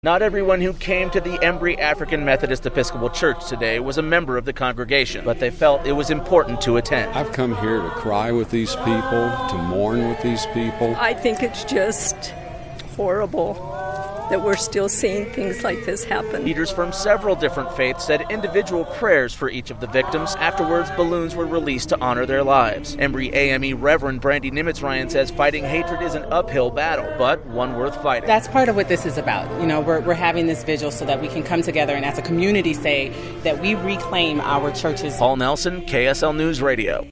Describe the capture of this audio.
Religious leaders in Ogden hold a memorial service for the victims of the Charleston shooting